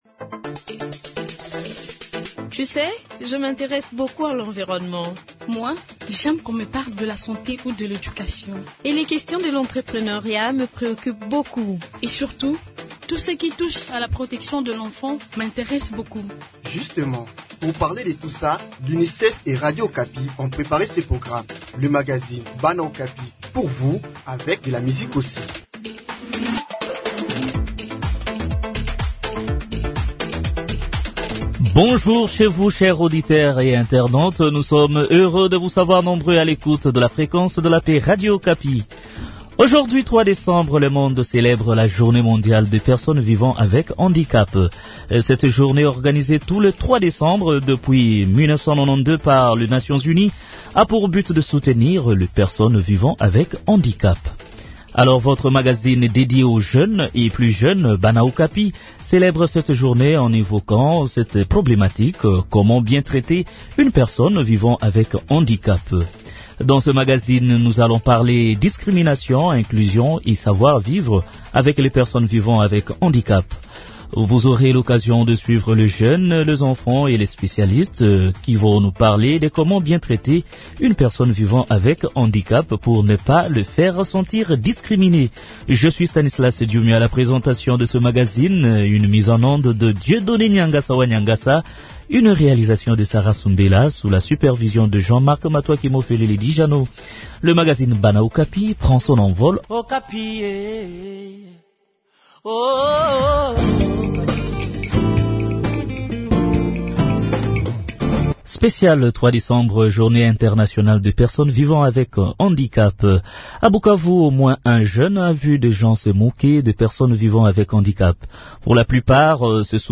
Dans ce magazine nous parlons discrimination, inclusion et savoir vivre avec les personnes vivant avec handicap. Vous aurez l’occasion de suivre les jeunes, les enfants et les spécialistes nous parler de Comment bien traiter une personne handicapés pour ne pas le faire sentir discriminé.